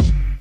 Kick (We Got Love).wav